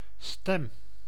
Ääntäminen
IPA: /stɛm/